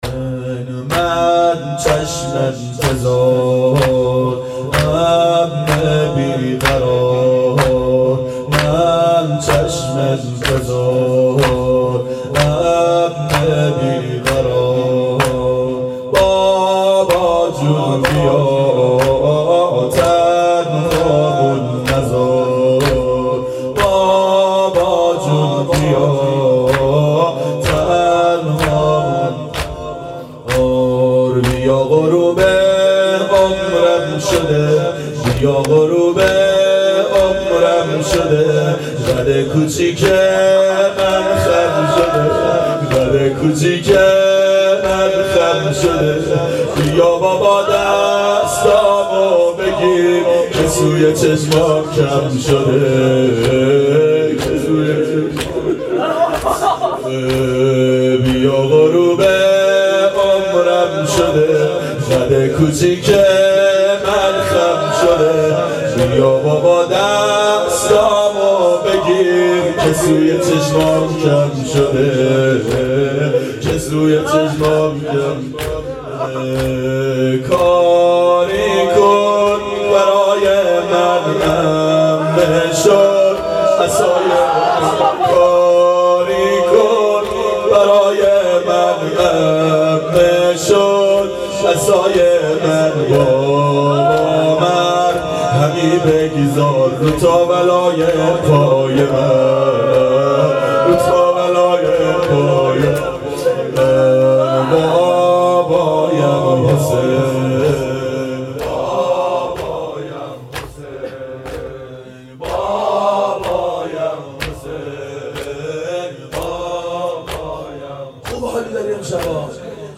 بابایم حسین - زمینه
شب اربعین 92 هیأت عاشقان اباالفضل علیه السلام منارجنبان